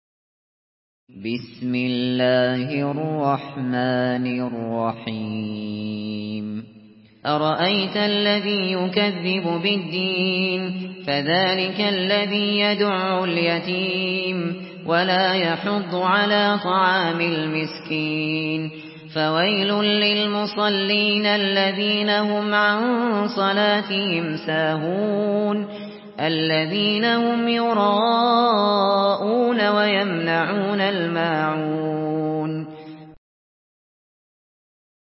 Surah الماعون MP3 by أبو بكر الشاطري in حفص عن عاصم narration.
مرتل